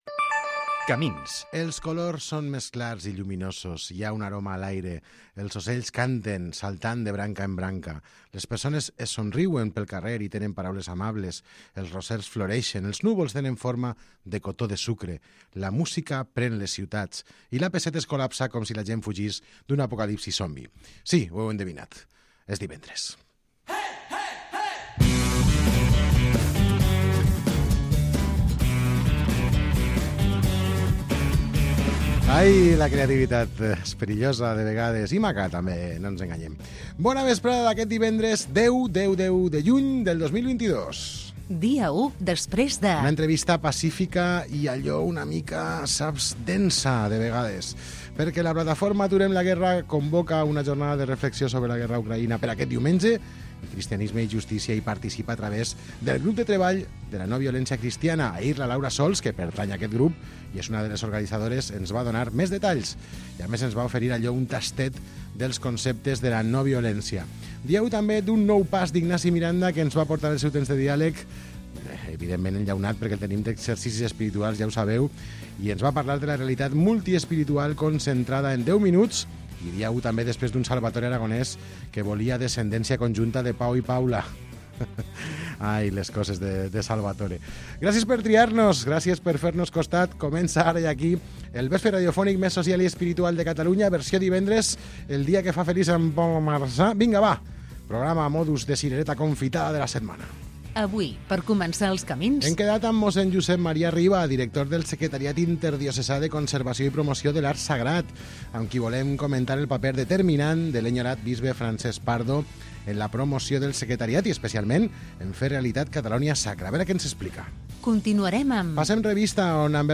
Magazin del vespre que ofereix una àmplia varietat de temes que van des de l'actualitat del dia, entrevistes, seccions culturals, socials i esports, passant per l'anàlisi i l'humor. Tot amb un to natural i desenfadat, per acabar el dia amb bones sensacions.